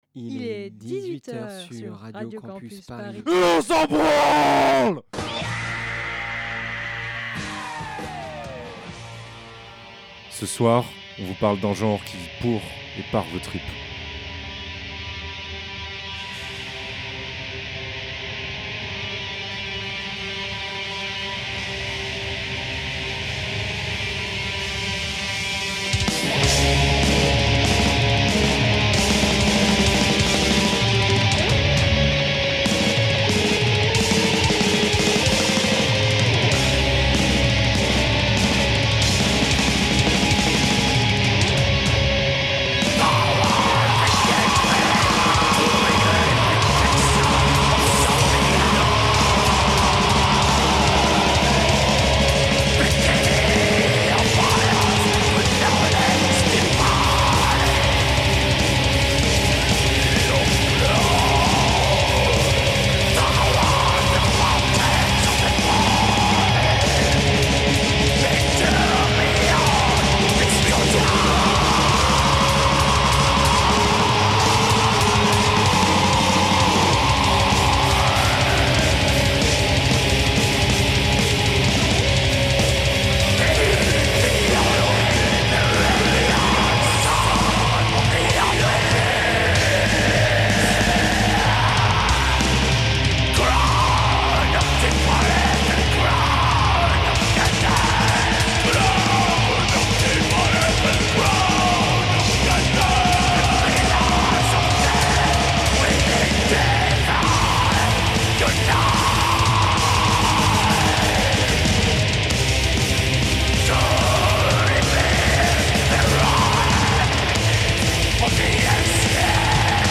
Black Metal
Les sonorités brutes, saturées, le chant écorché n'appellent pas au plaisir mais expriment au contraire l'angoisse et la souffrance des âmes torturées par la lucidité.